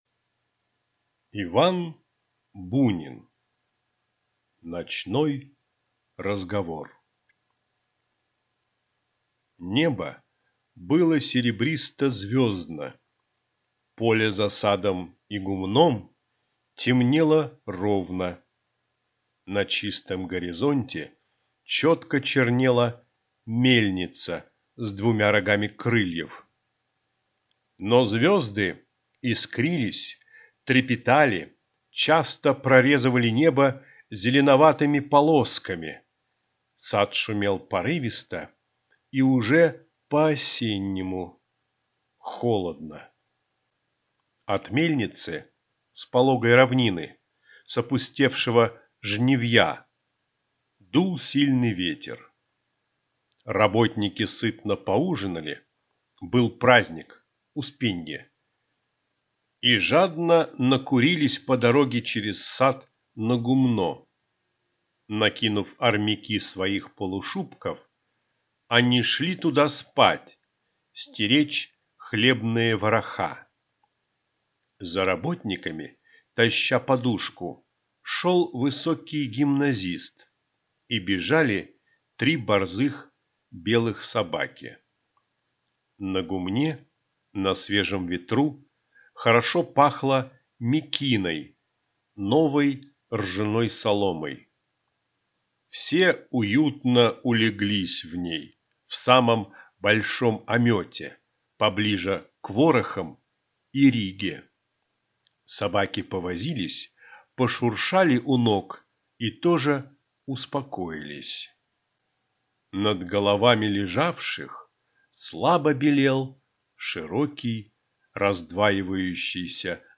Аудиокнига Ночной разговор | Библиотека аудиокниг
Читает аудиокнигу